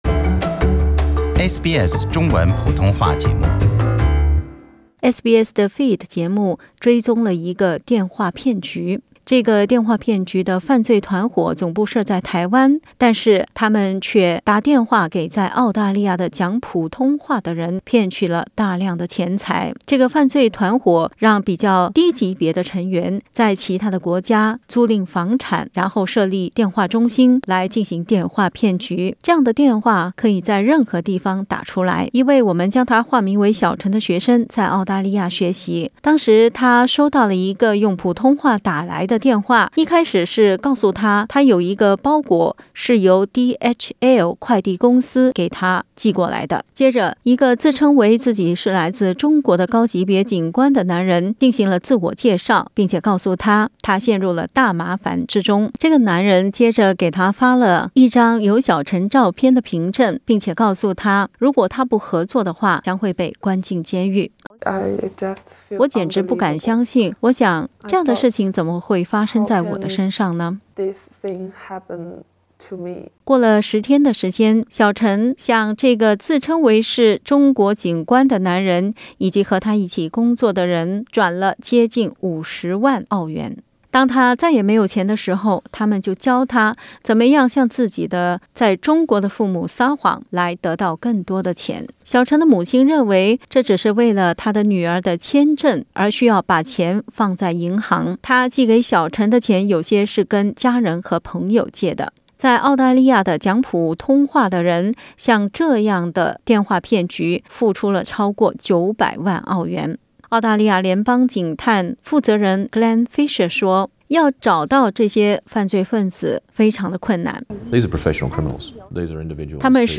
Another elaborate telephone scam targeting Mandarin speakers has netted millions of dollars for the crime syndicates behind it. An investigation by SBS's The Feed has looked at the anatomy of the scam and spoken to an Australian victim, who tells of the "mind-control" tactics used by the syndicates that led her to lose a fortune.